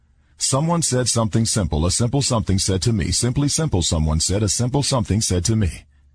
tongue_twister_01_02.mp3